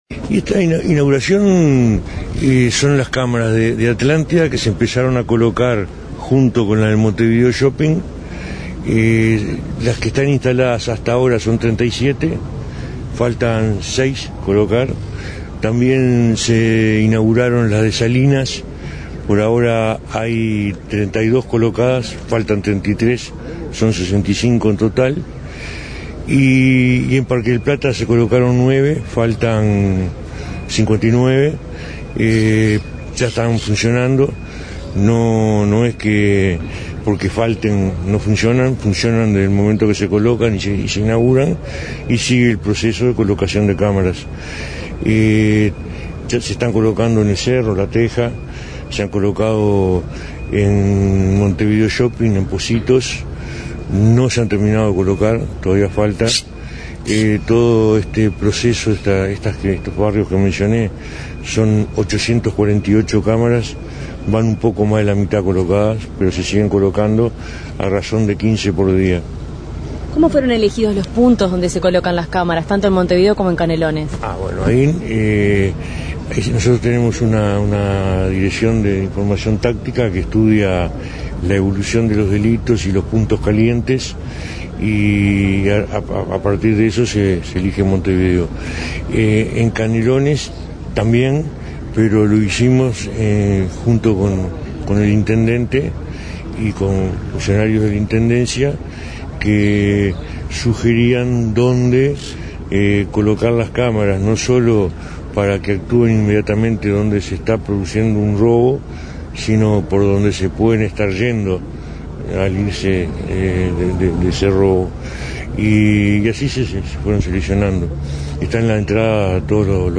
El ministro del Interior, Eduardo Bonomi, habló de la disminución de un 73 % de las rapiñas en Ciudad Vieja, Montevideo, desde 2013.